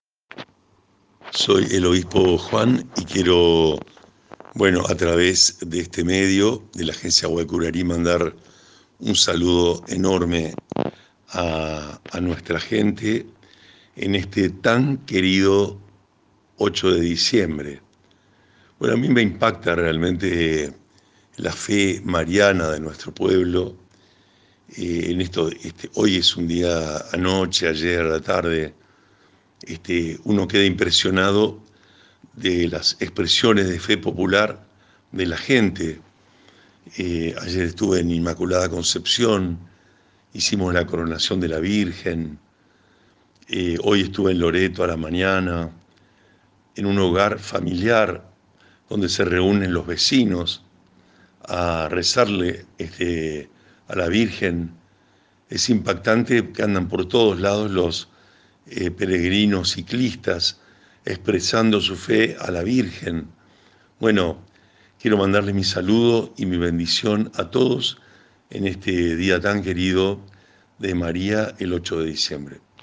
El Obispo Juan Rubén Martínez, a través de la Agencia Guacurarí, saludó a la gente en este 8 de diciembre - Agencia de Noticias Guacurari